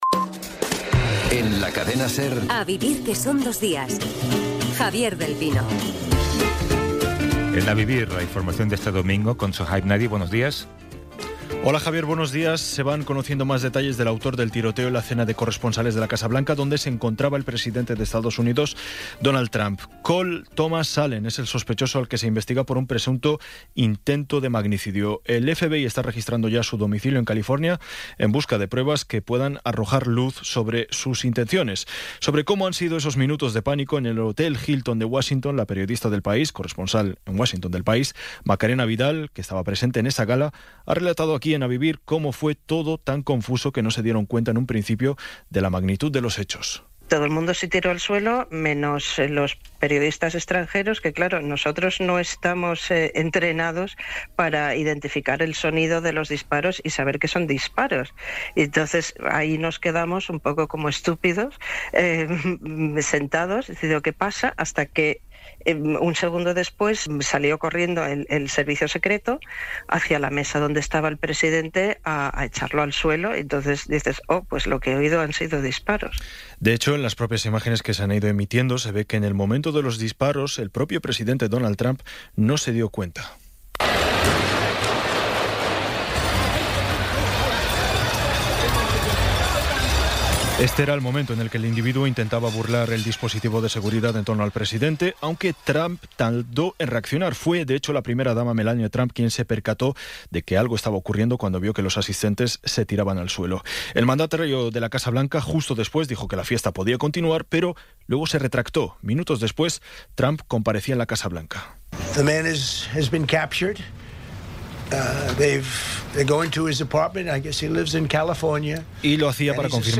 Resumen informativo con las noticias más destacadas del 26 de abril de 2026 a las nueve de la mañana.